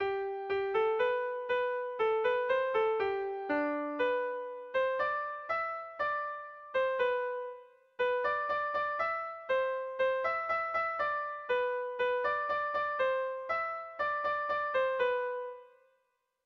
Bertso melodies - View details   To know more about this section
Erromantzea